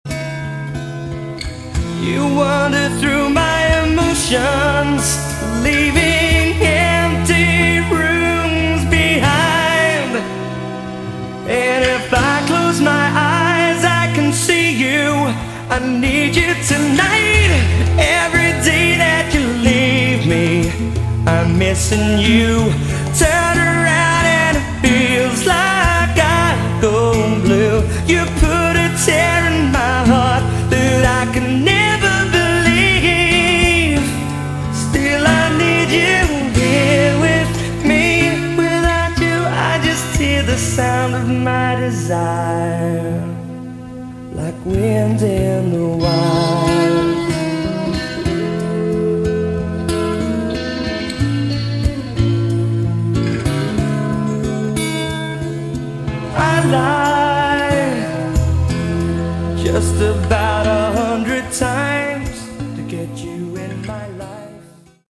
Category: Melodic Rock
Second CD consists of unreleased demos.